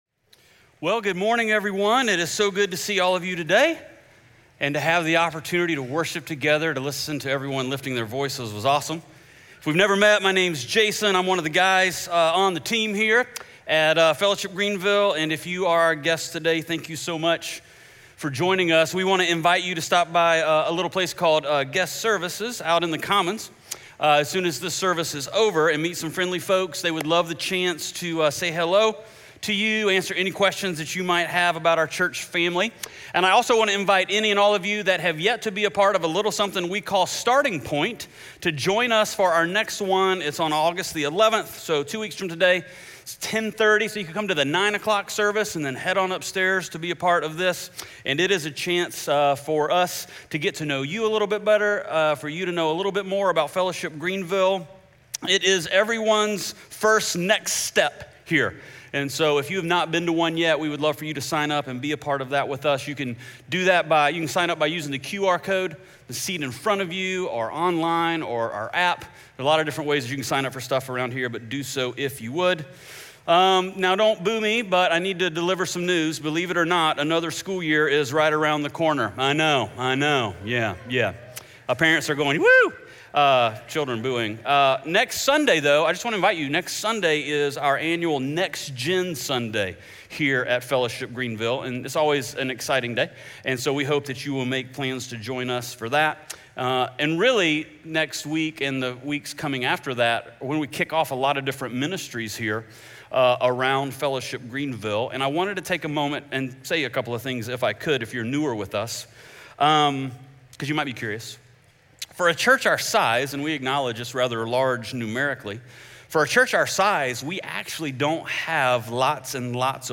SERMON SCREENSHOTS & KEY POINTS